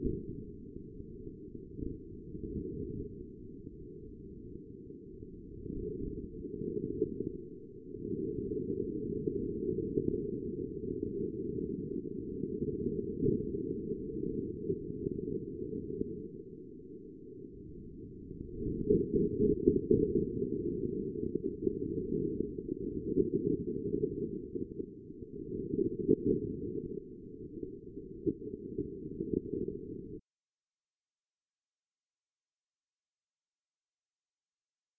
LISTEN to rover on the move